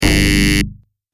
دانلود آهنگ خطا 1 از افکت صوتی اشیاء
دانلود صدای خطا 1 از ساعد نیوز با لینک مستقیم و کیفیت بالا
جلوه های صوتی